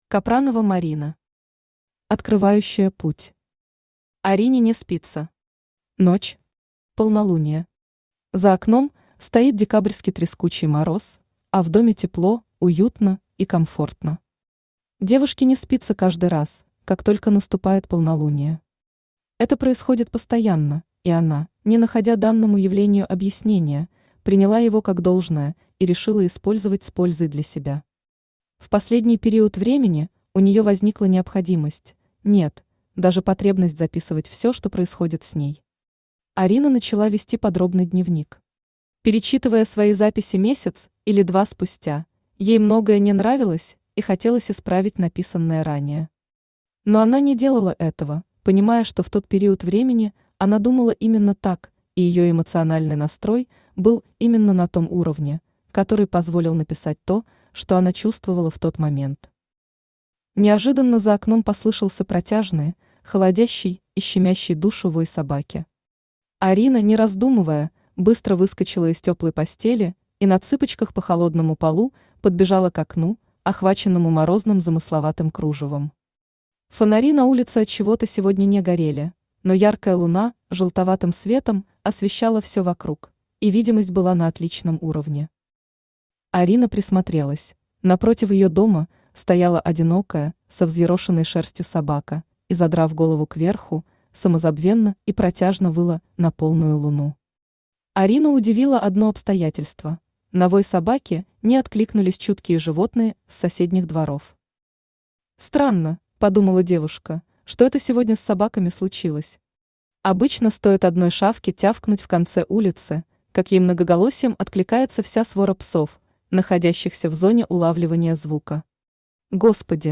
Аудиокнига Открывающая путь | Библиотека аудиокниг
Aудиокнига Открывающая путь Автор Марина Капранова Читает аудиокнигу Авточтец ЛитРес.